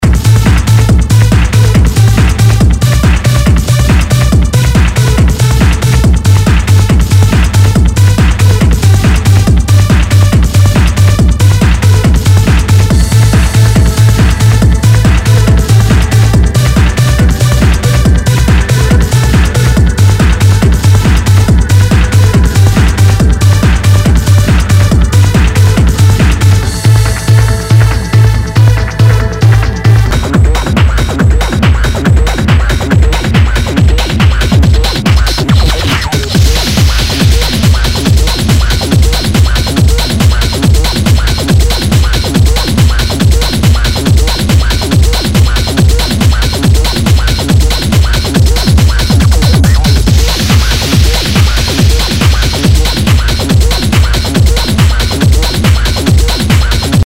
HOUSE/TECHNO/ELECTRO
ハード・トランス / テクノ！
[VG ] 平均的中古盤。スレ、キズ少々あり（ストレスに感じない程度のノイズが入ることも有り）